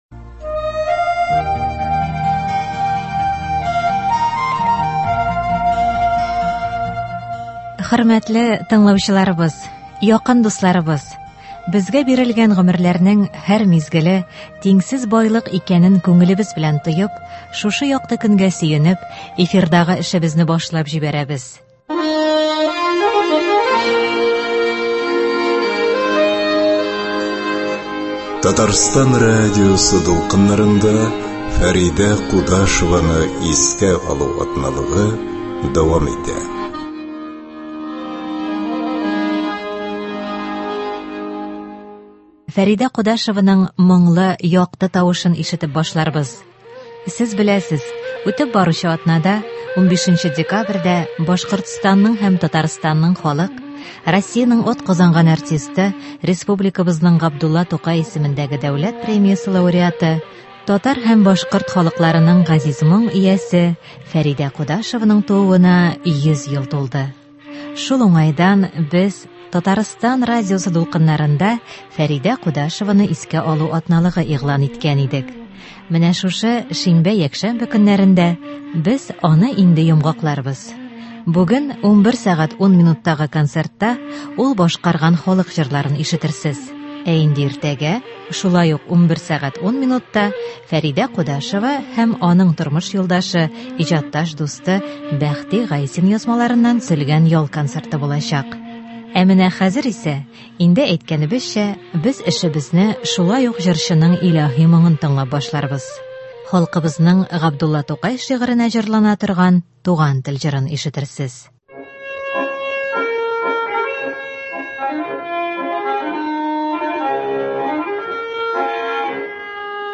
Хәзер исә эшебезне җырчының илаһи моңын тыңлап башларбыз.